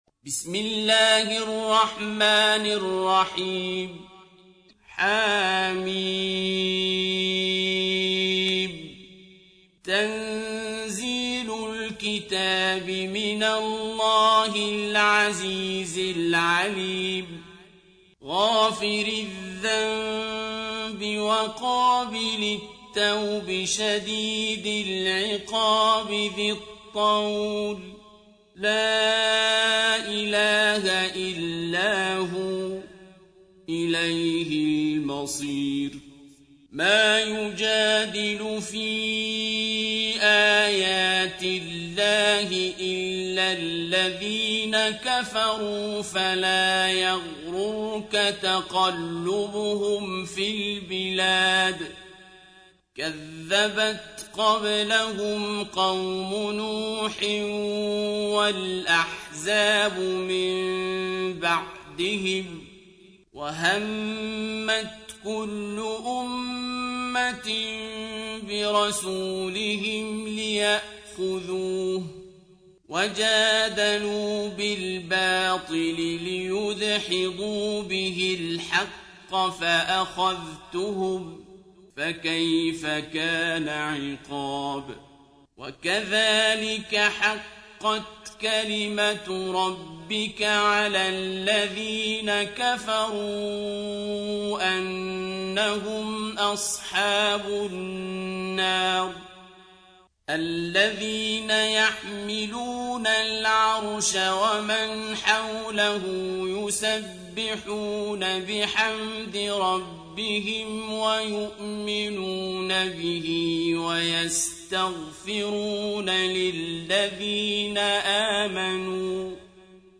سورة غافر | القارئ عبدالباسط عبدالصمد